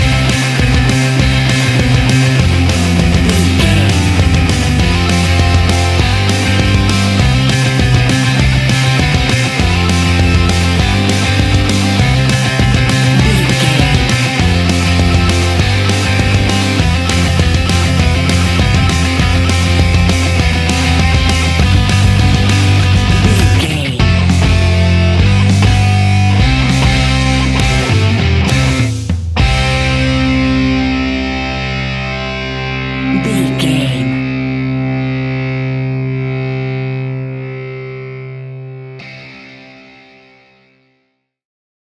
Uplifting
Ionian/Major
Fast
hard rock
punk metal
instrumentals
Rock Bass
heavy drums
distorted guitars
hammond organ